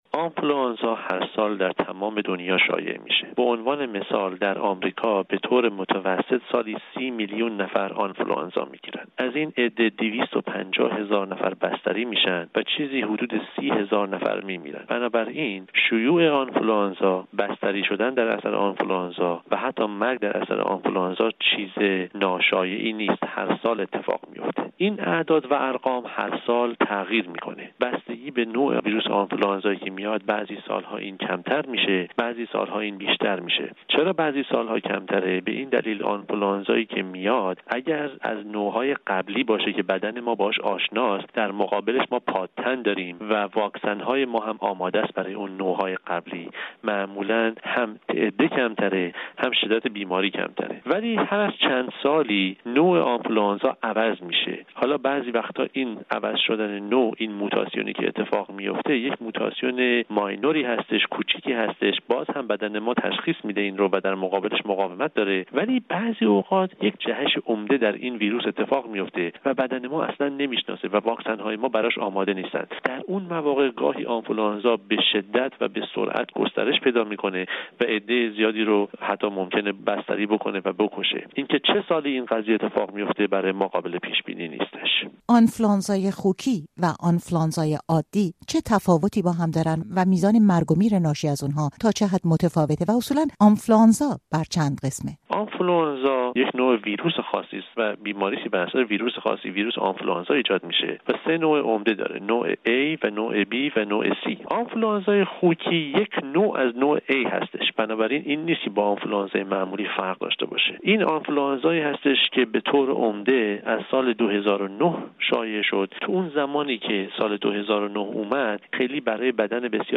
دلایل شیوع آنفلوآنزای خوکی در گفت‌وگو با یک متخصص اپیدمی